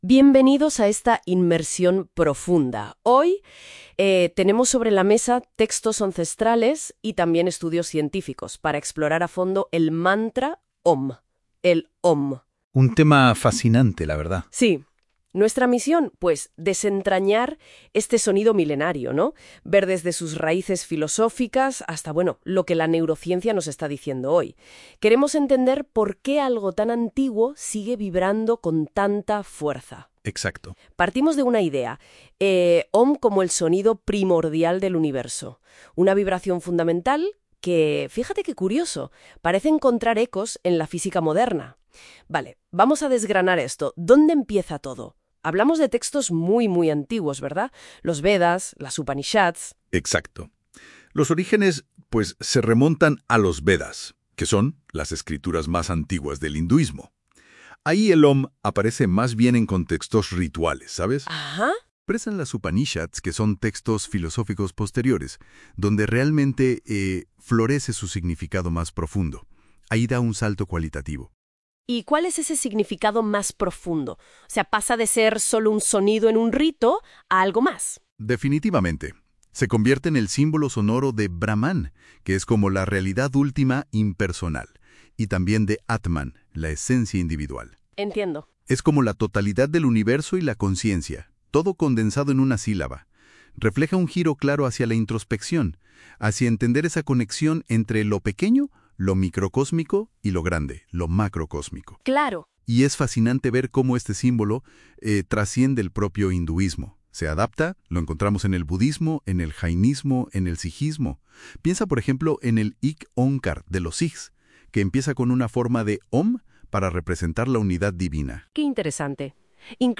Mantra-Om.mp3